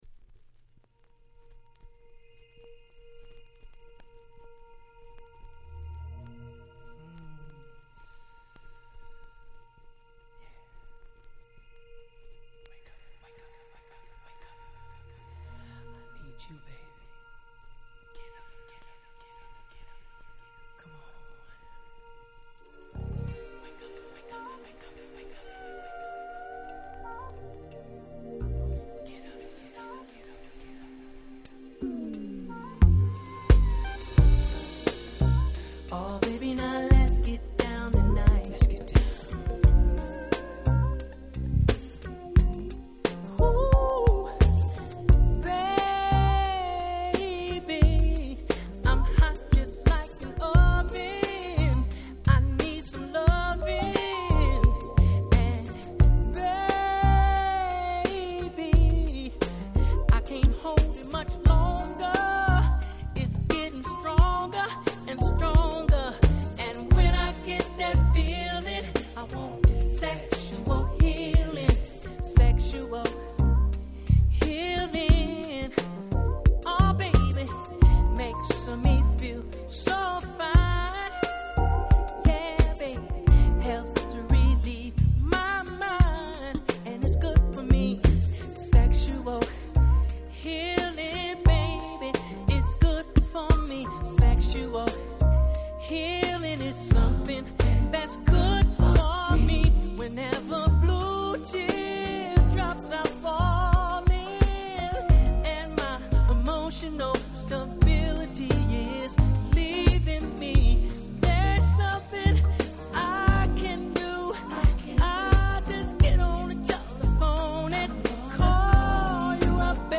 96' Nice Cover R&B !!
これが悪いはずもなく最高にMoodyな仕上がりに！！